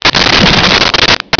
Sfx Poof1
sfx_poof1.wav